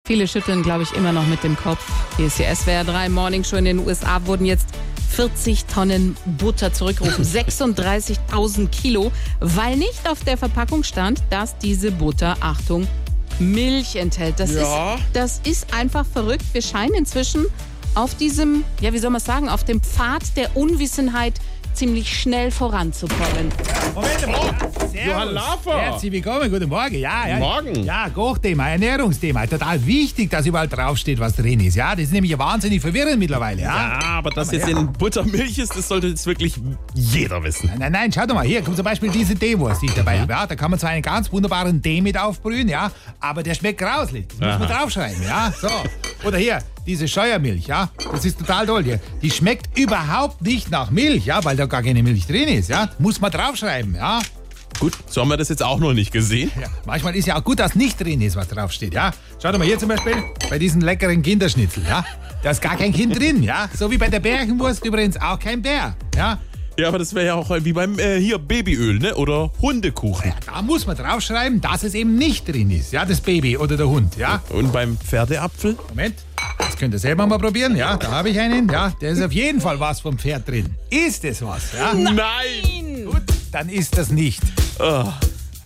SWR3 Comedy In Butter ist Milch